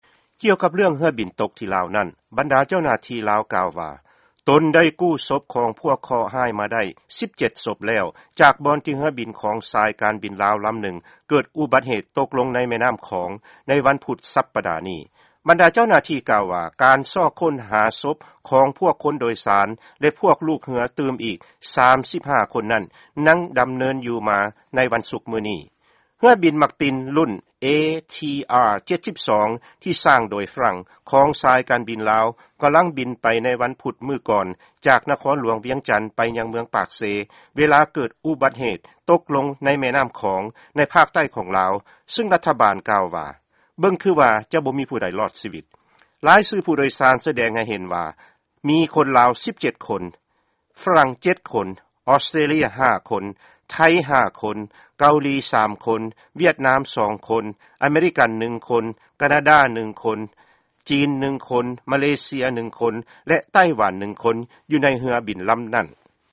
ຟັງຂ່າວ ເຮືອບິນຕົມໃນ ສປປ ລາວ